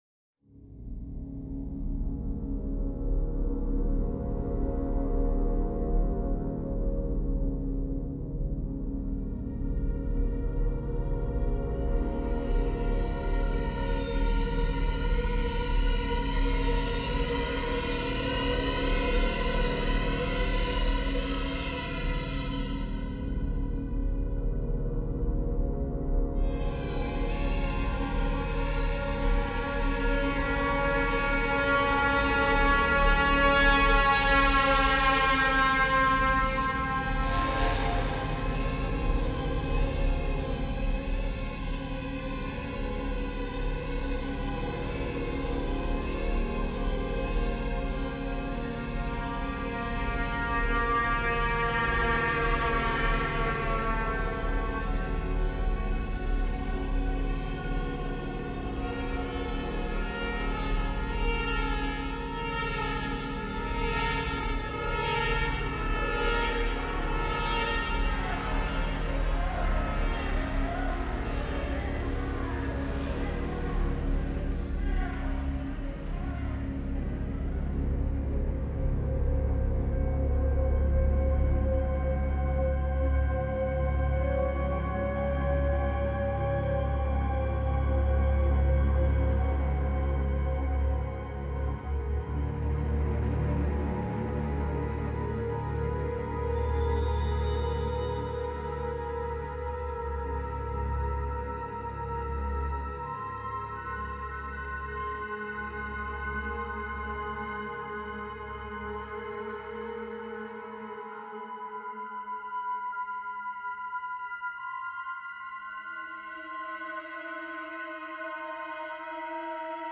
/ 01 - Ambience / Ambience 5 - Other Passenge... 34 MiB Raw Permalink History
Ambience 5 - Other Passenger.wav